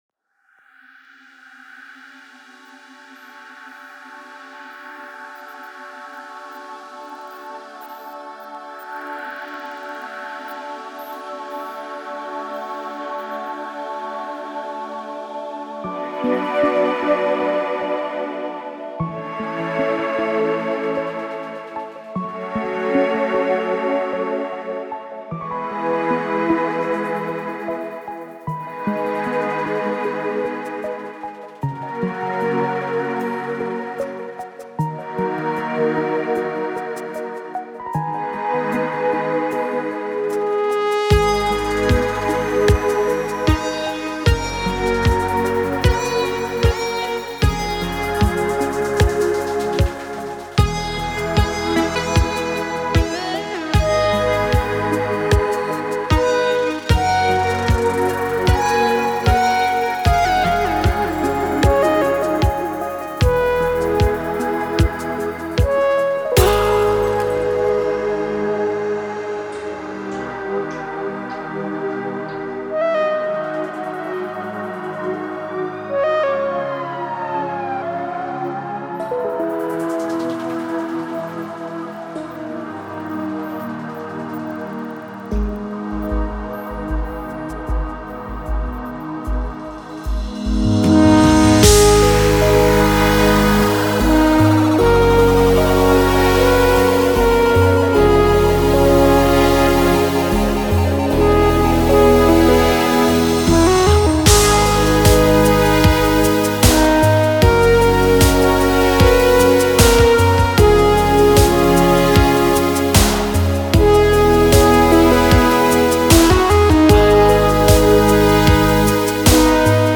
It's a remix of this: